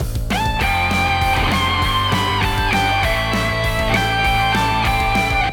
アレンジテクニック ソロ
アレンジテクニック-ソロ.wav